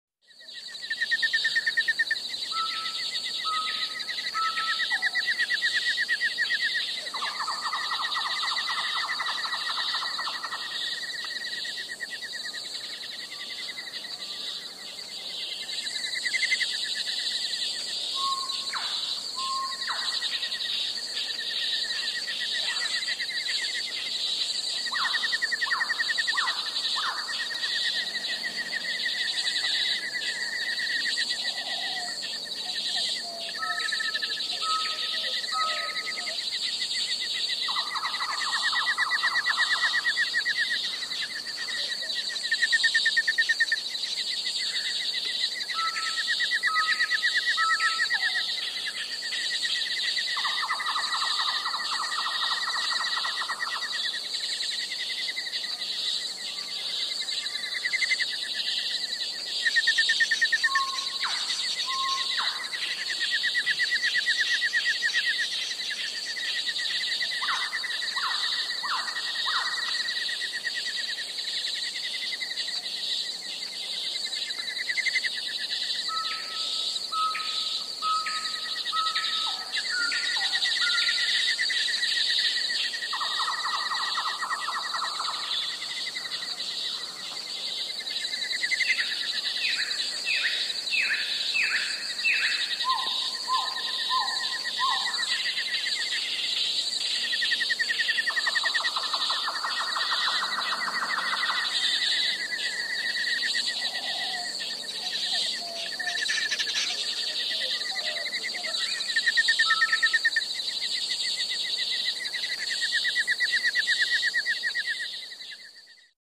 Шепот ночной дикой природы